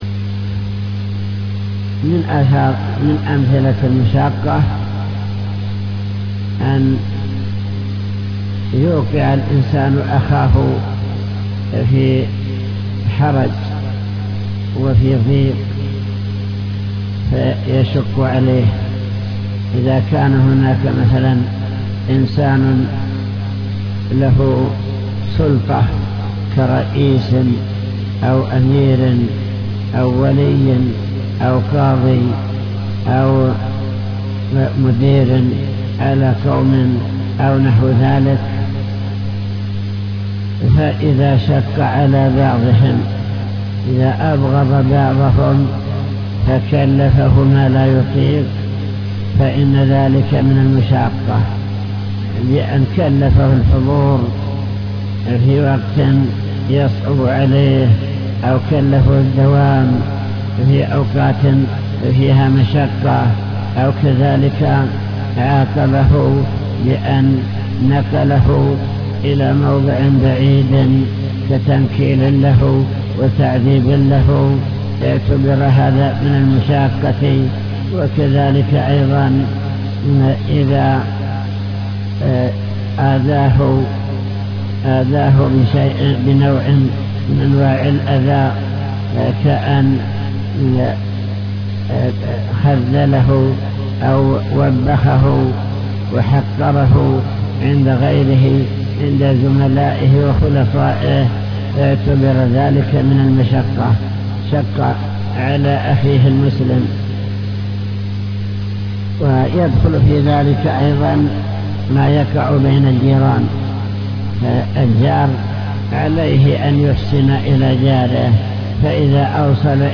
المكتبة الصوتية  تسجيلات - كتب  شرح كتاب بهجة قلوب الأبرار لابن السعدي شرح حديث من ضار ضار الله به